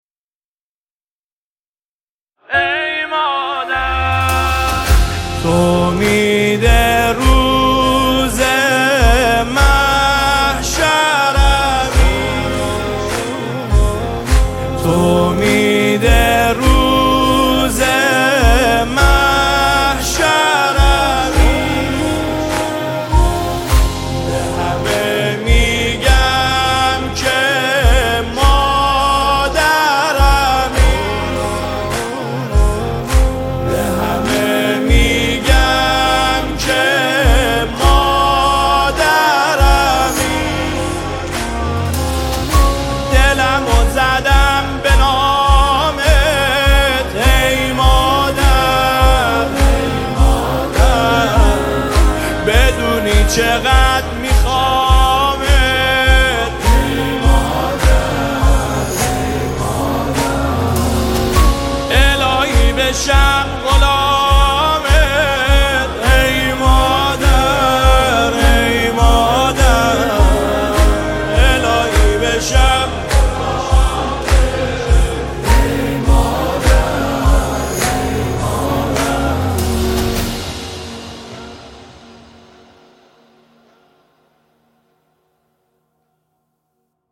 نماهنگ دلنشین
نماهنگ مذهبی مداحی مذهبی